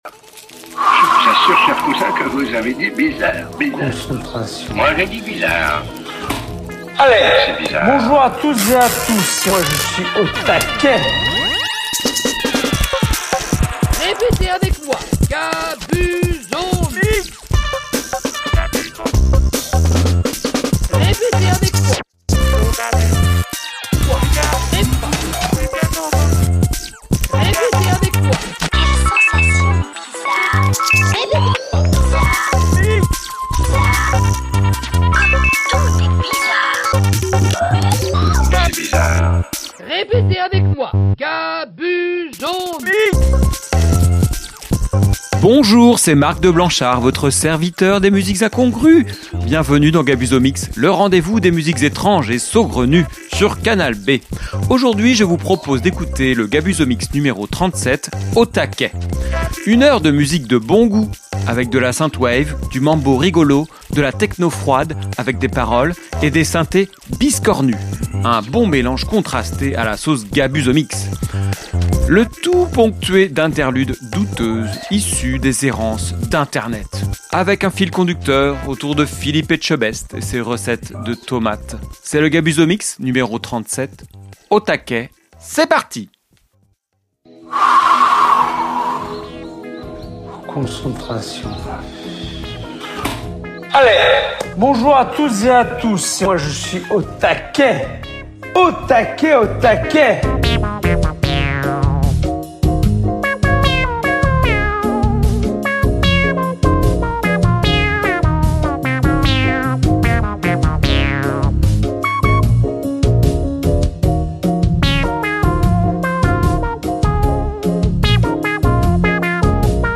avec des interludes douteuses issues d'errances INTERNET.